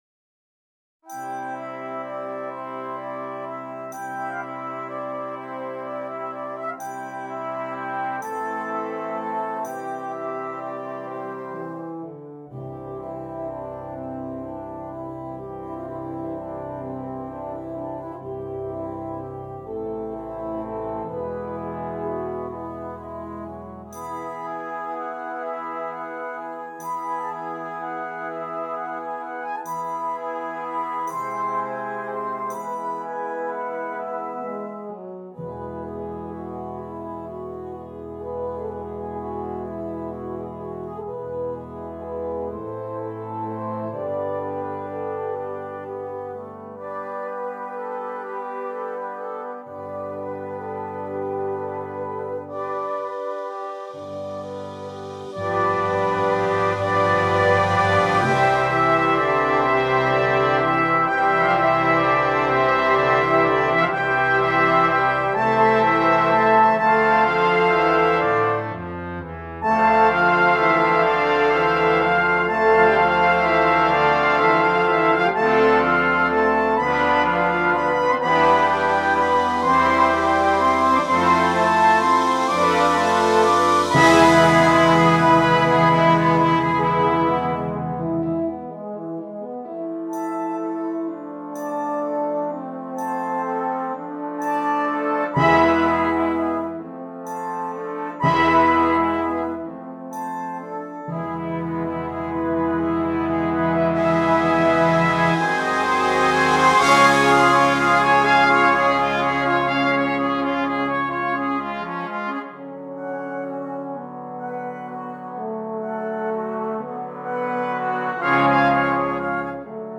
Brass Choir
famous flowing melody